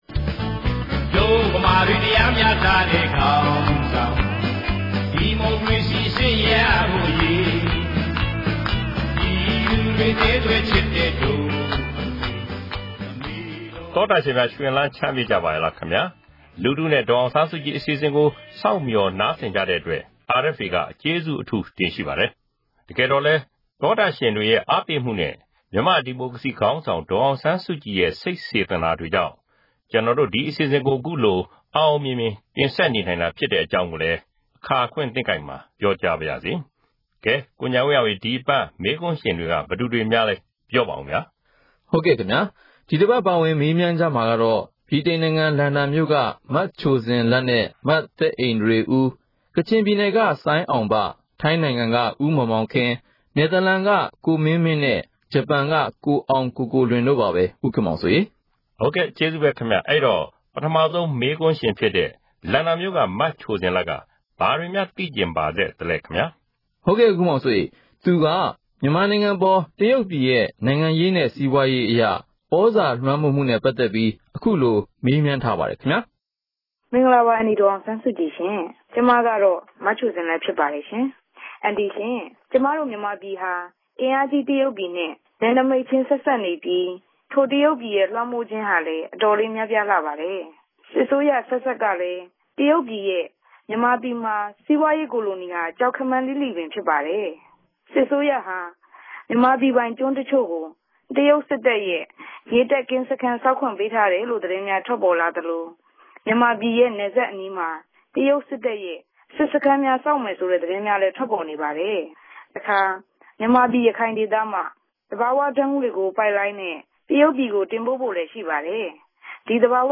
‘လူထုနှင့် ဒေါ်အောင်ဆန်းစုကြည်’ အပတ်စဉ်အမေးအဖြေ